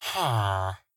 Minecraft Version Minecraft Version 1.21.4 Latest Release | Latest Snapshot 1.21.4 / assets / minecraft / sounds / mob / villager / idle2.ogg Compare With Compare With Latest Release | Latest Snapshot